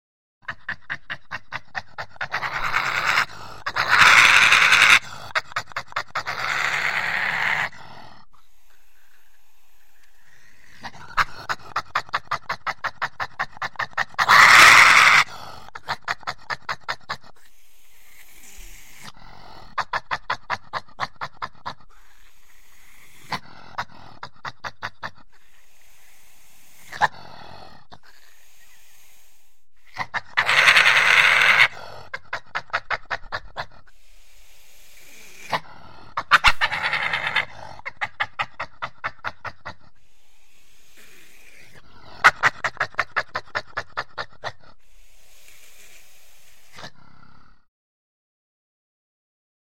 Звуки барсука